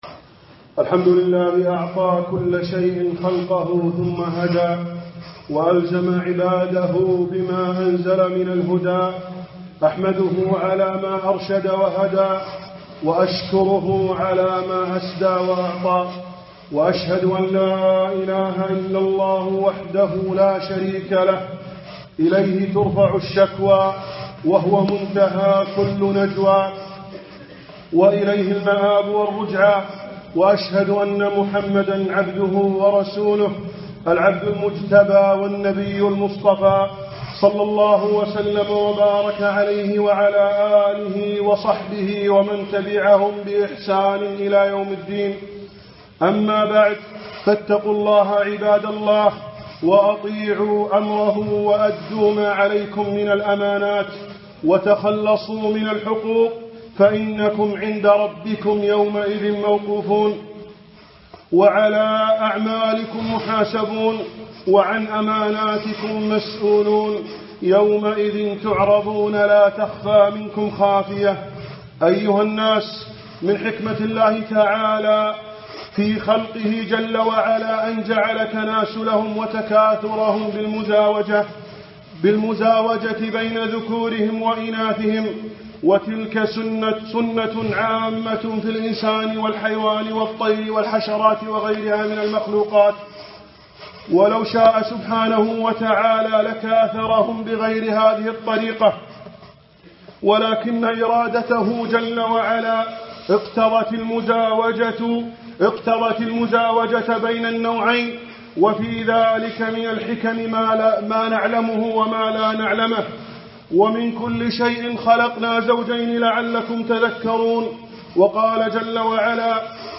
ألقيت هذه الخطبة يوم الجمعة 6/ 3 / 2015 في مسجد الأحسان صبحان